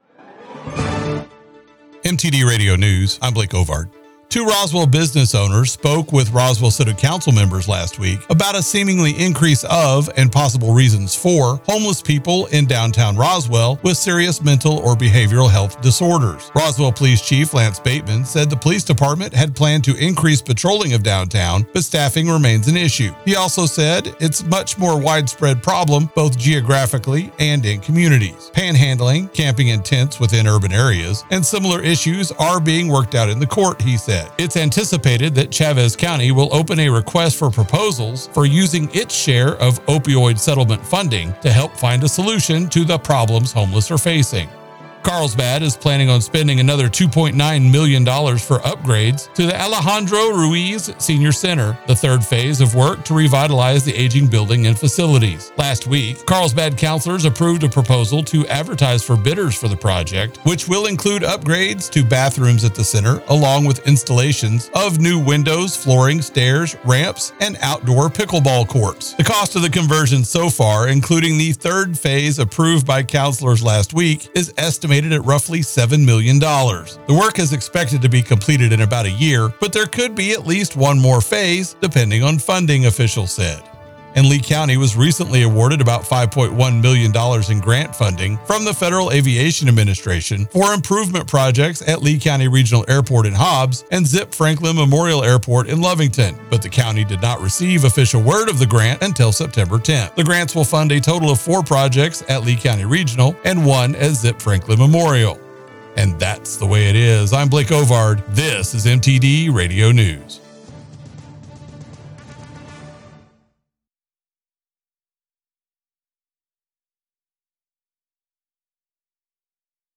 107.1 The Blaze News – New Mexico and West Teaxs